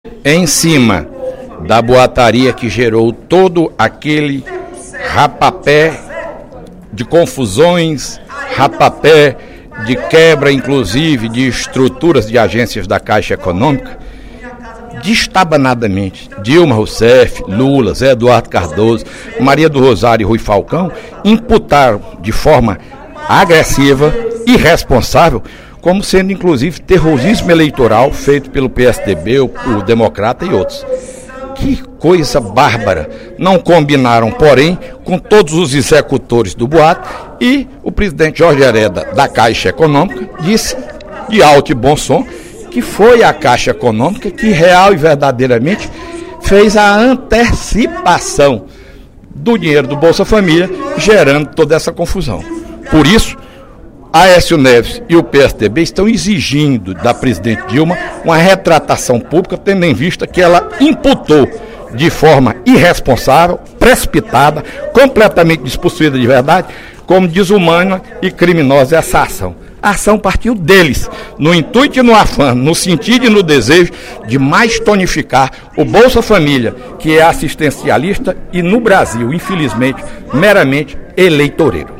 No primeiro expediente da sessão plenária desta quarta-feira (29/05), o deputado Fernando Hugo (PSDB) afirmou que os boatos de que o Bolsa Família seria extinto partiram do PT, no intuito de “tonificar o programa”.